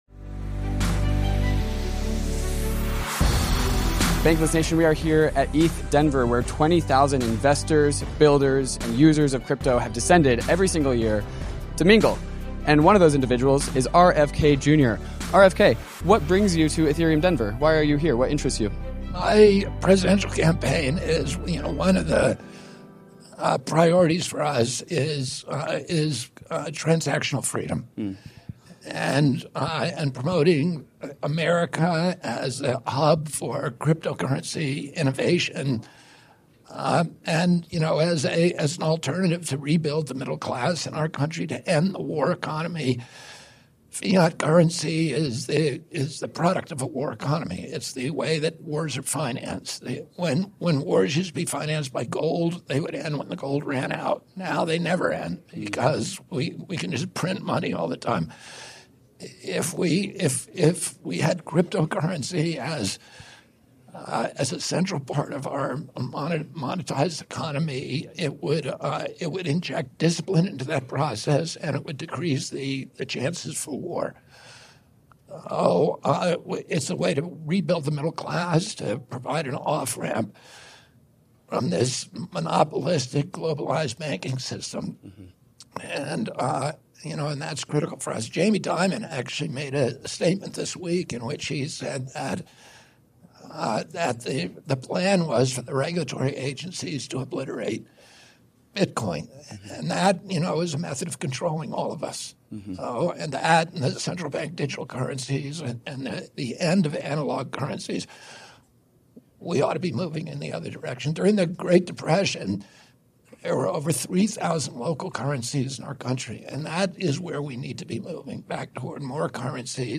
The RFK Interview
An in person interview with presidential candidate RFK Jr. who shares his takes and policies on the state of crypto in the United States.